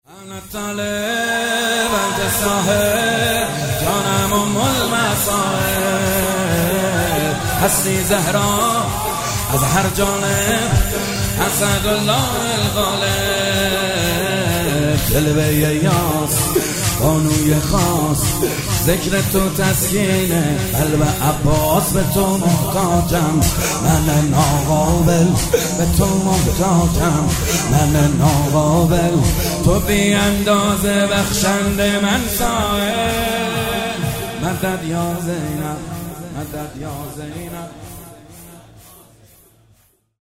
کربلایی محمدحسین حدادیان
شهادت حضرت زینب -شور - جانم ام المصایب - محمد حسین حدادیان